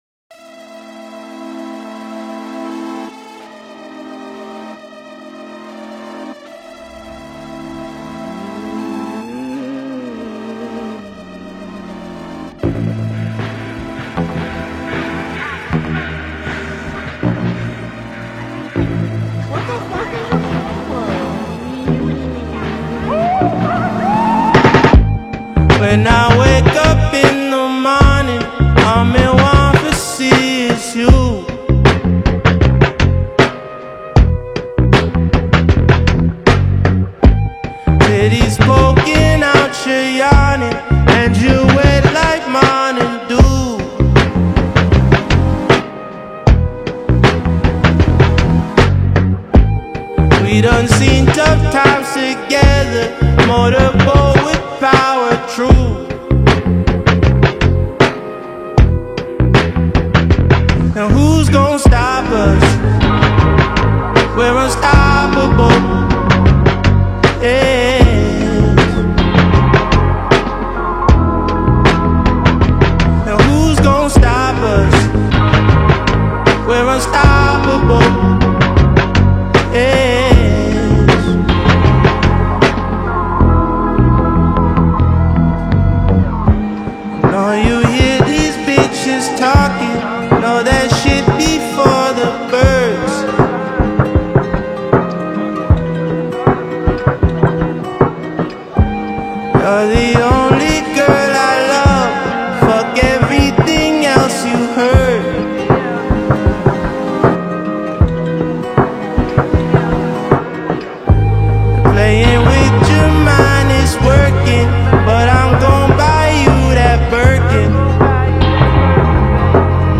uplifting new single
has a catchy beat